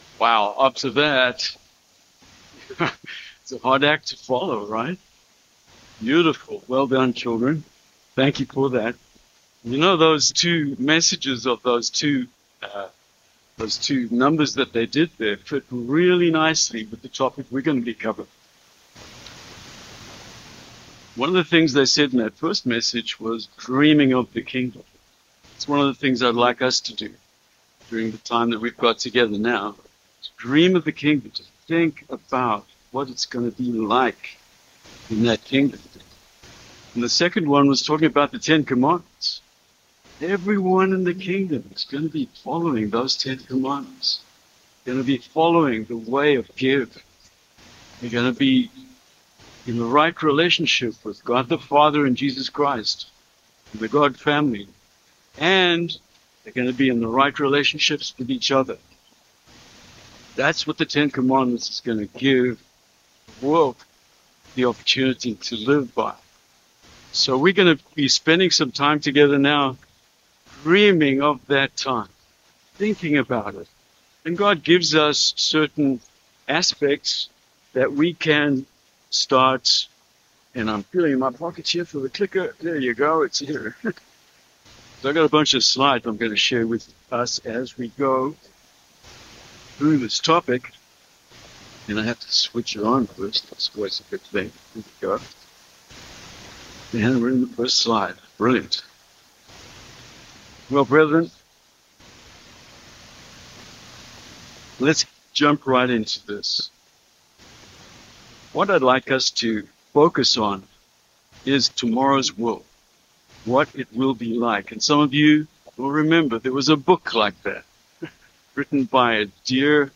Sermons
Given in Aransas Pass, Texas